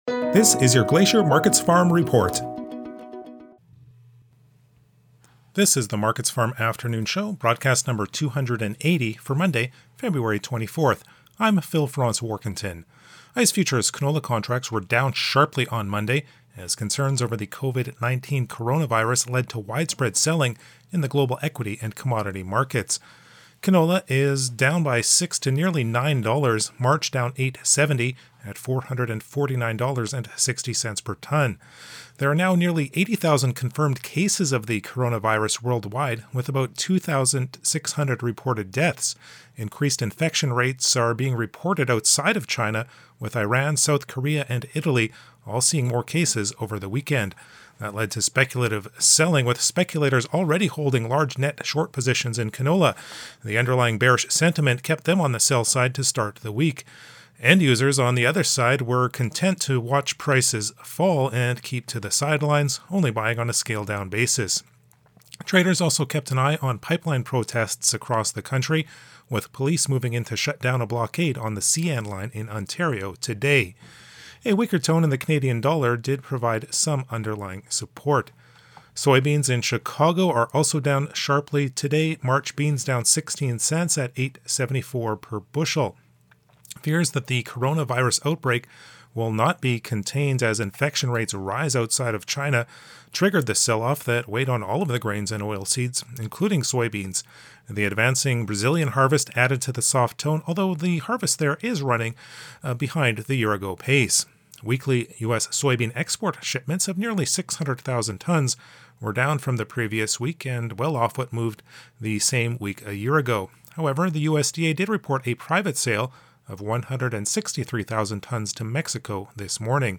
MarketsFarm's radio show is delivered twice per day - at noon and at the close of markets - and contains the latest information on the price of canola, wheat, soybeans, corn and specialty crops.